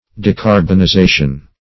Search Result for " decarbonization" : The Collaborative International Dictionary of English v.0.48: Decarbonization \De*car`bon*i*za"tion\, n. The action or process of depriving a substance of carbon.